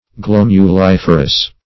Search Result for " glomuliferous" : The Collaborative International Dictionary of English v.0.48: Glomuliferous \Glom`u*lif"er*ous\, a. [L. glomus a ball + -ferous.]
glomuliferous.mp3